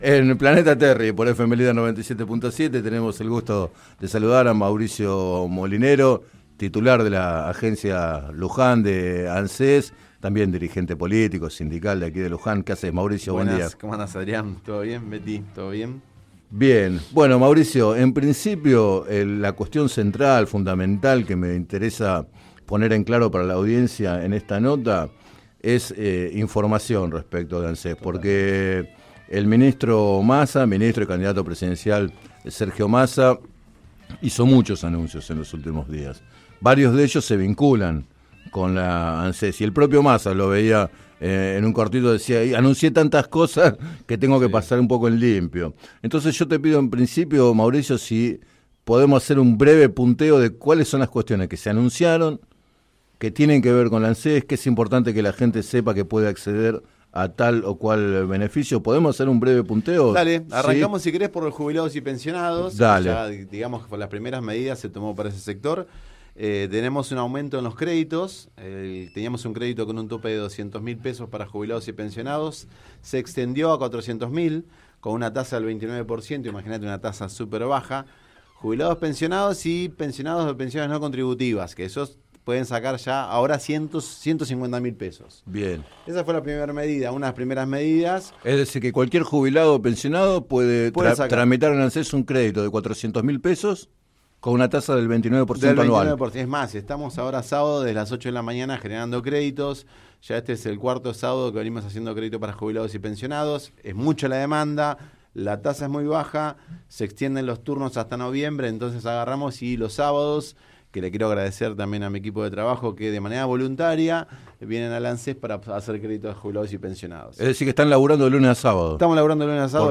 Entrevistado en el programa Planeta Terri de FM Líder 97.7